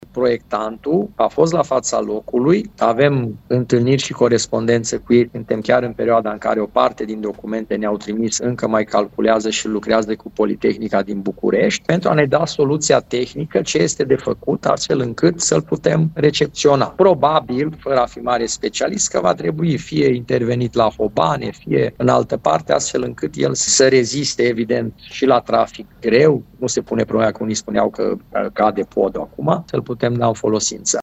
Primarul Călin Bibarț spune că așteaptă să afle dacă au fost erori de proiectare sau de construcție.